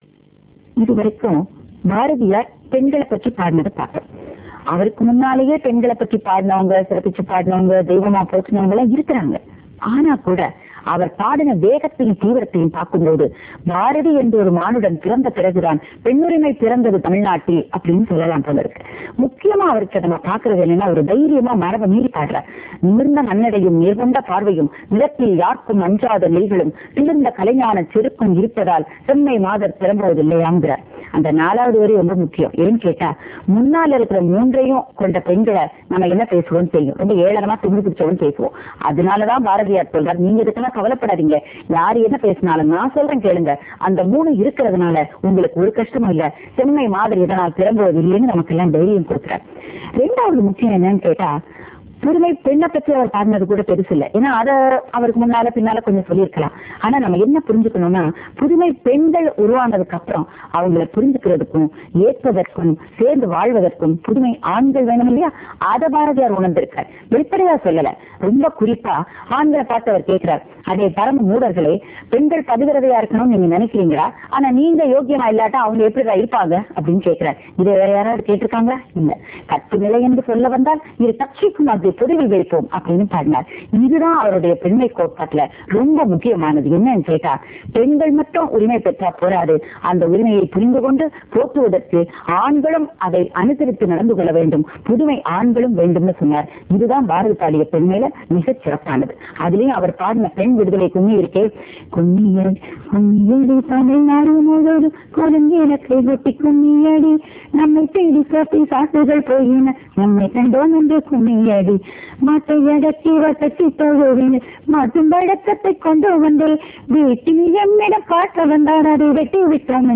சிறப்புரை